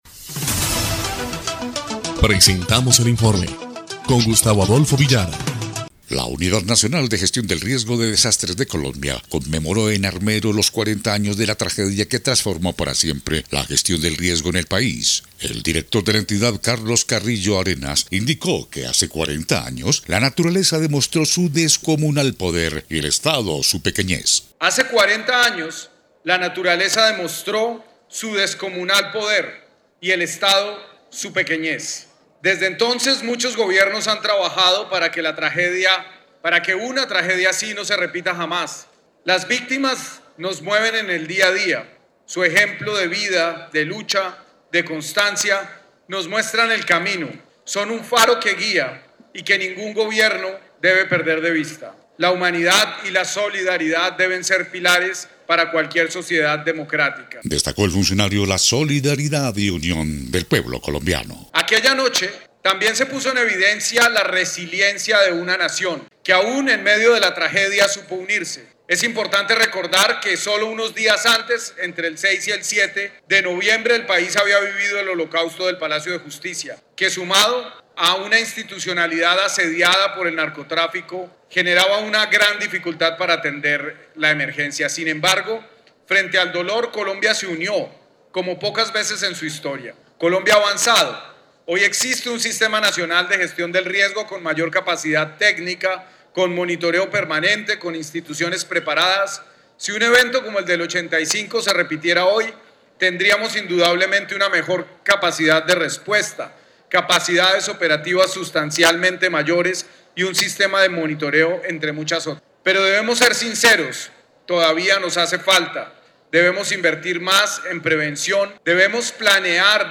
EL INFORME 1° Clip de Noticias del 14 de noviembre de 2025